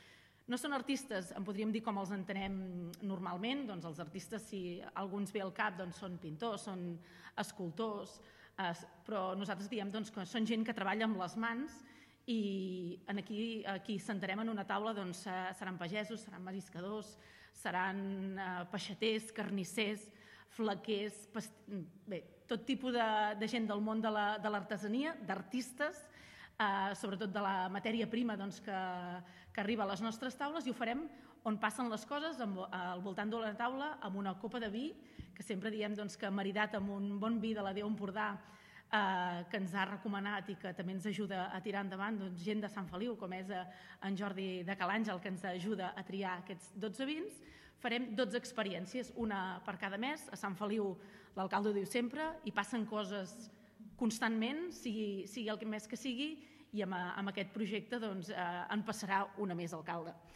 Ho explica la regidora de promoció econòmica del municipi, Núria Cucharero.